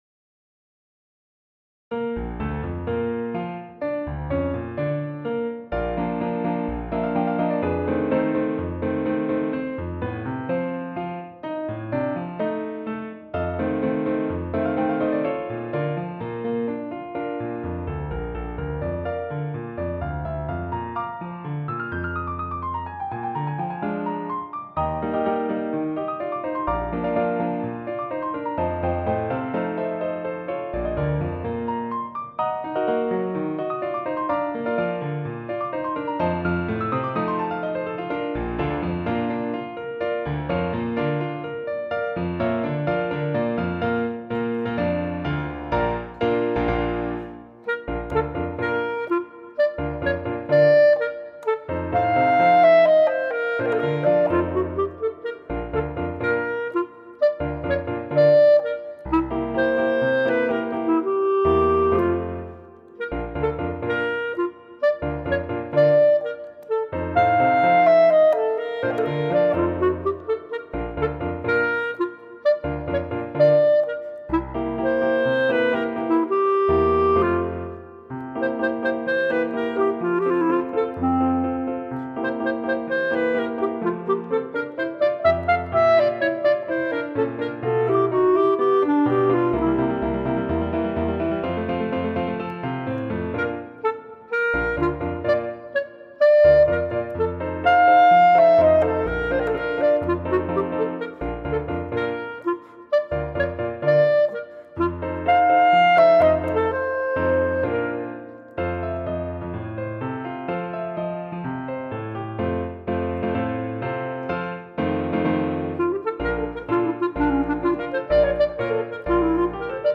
for Bb Clarinet & Piano.